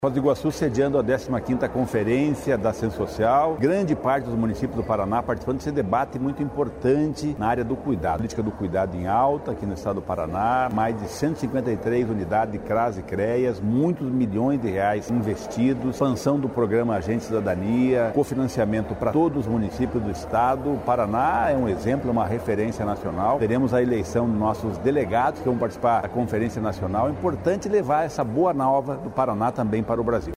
Sonora do secretário estadual do Desenvolvimento Social e Família, Rogério Carboni, sobre ações para fortalecer a assistência social
ROGÉRIO CARBONI - CONFERENCIA FOZ.mp3